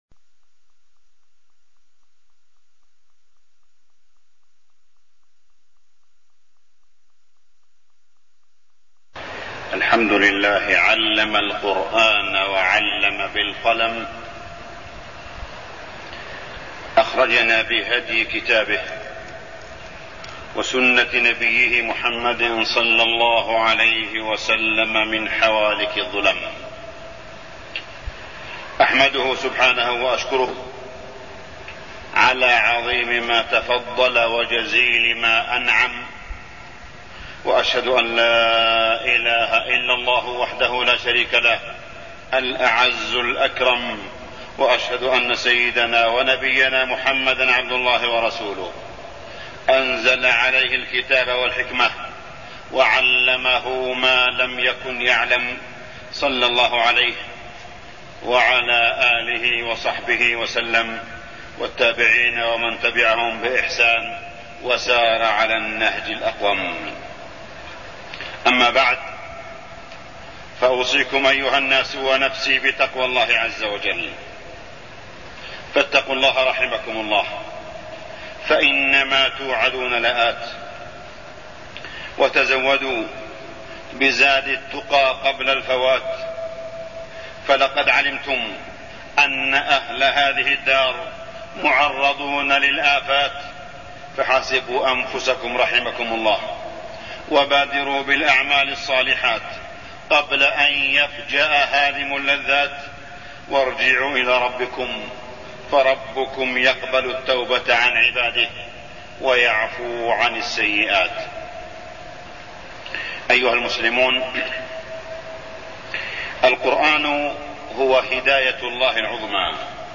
تاريخ النشر ١٨ جمادى الأولى ١٤٢١ هـ المكان: المسجد الحرام الشيخ: معالي الشيخ أ.د. صالح بن عبدالله بن حميد معالي الشيخ أ.د. صالح بن عبدالله بن حميد العزة في إتباع القرآن